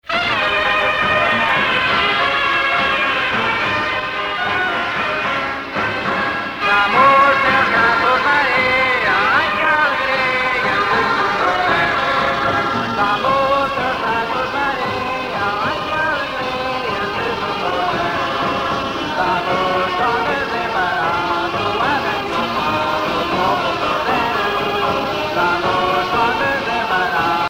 danse : vira (Portugal)
Pièce musicale éditée